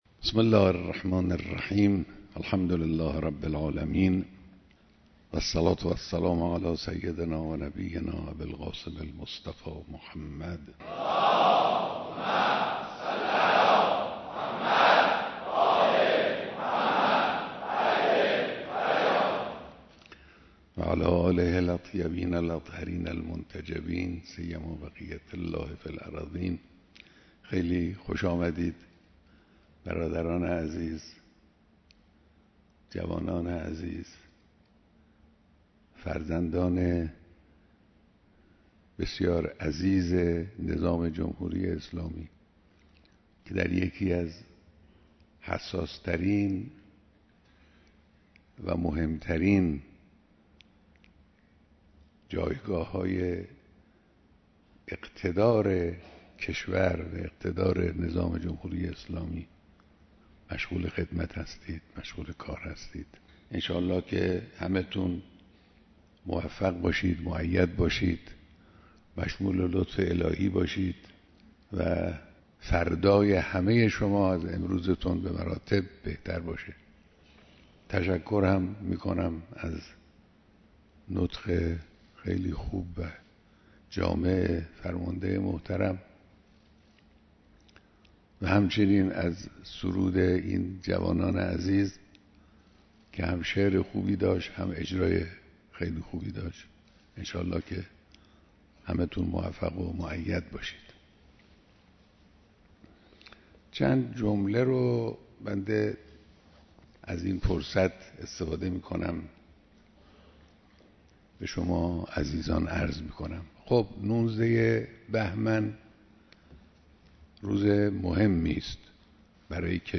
بیانات در دیدار فرماندهان نیروی هوایی و پدافند هوایی ارتش